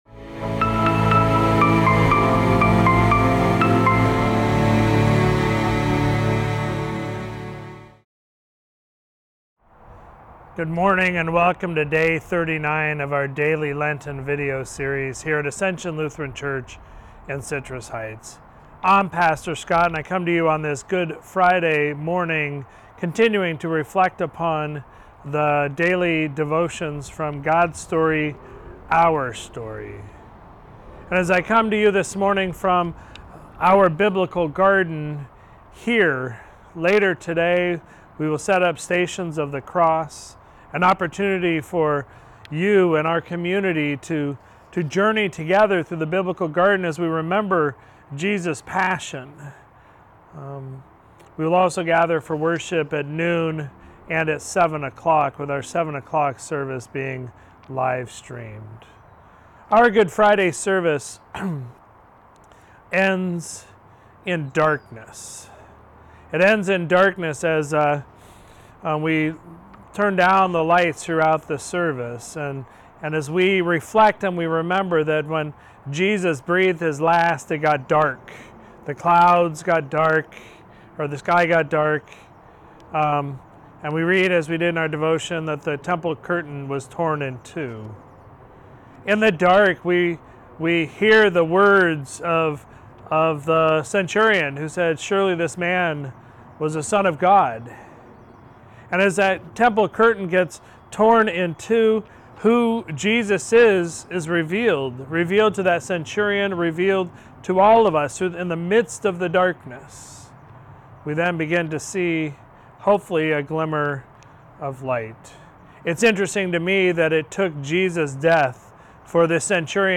Sermon for Sunday, February 12, 2023